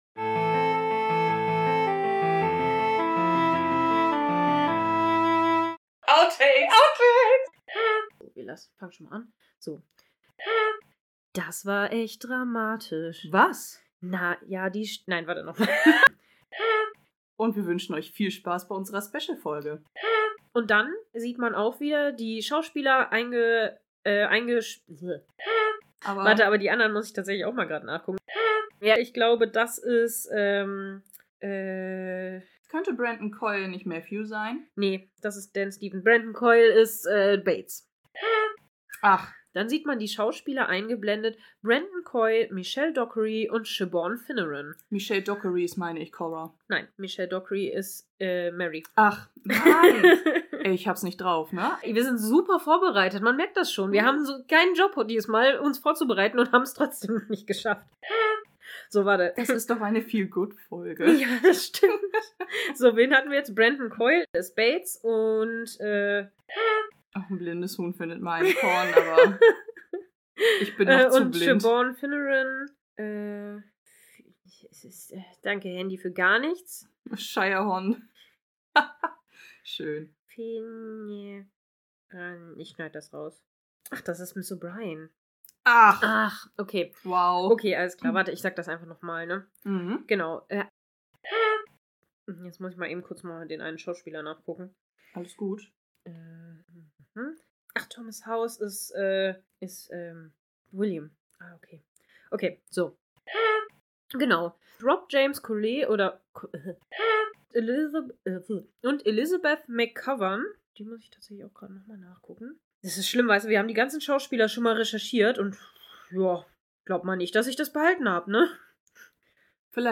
s02-Outtakes zur Staffelbesprechung